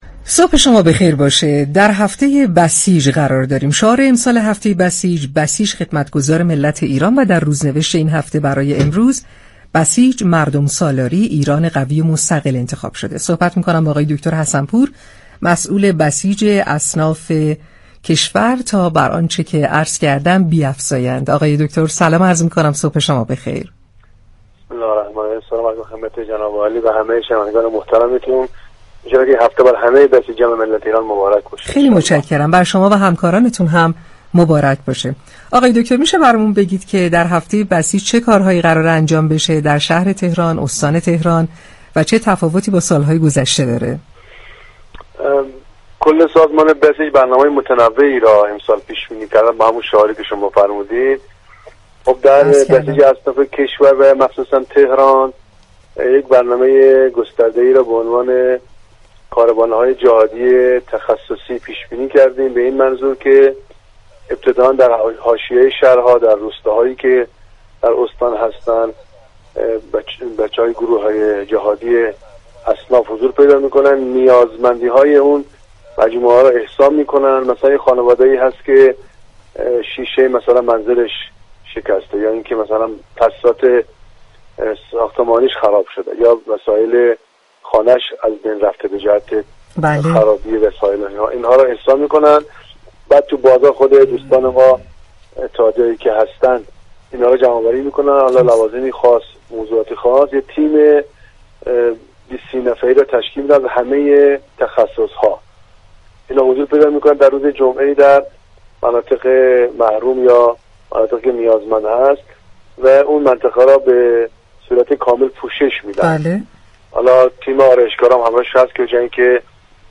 برنامه شهر آفتاب رادیو تهران به مناسبت هفته بسیج، امروز دوم آذرماه با غلامرضا حسن‌پور رئیس سازمان بسیج تجار، اصناف و بازاریان كشور گفت و گو كرد.